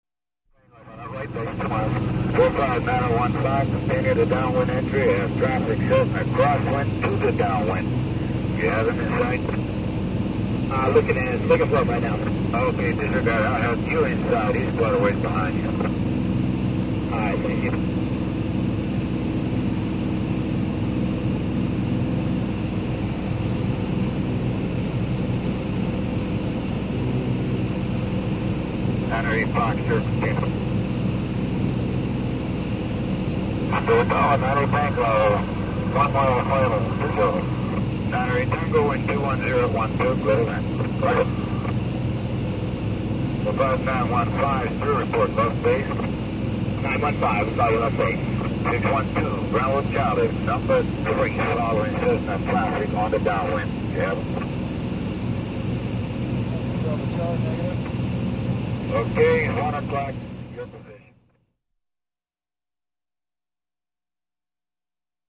Звуки самолетов
8. В кабине пилотов самолета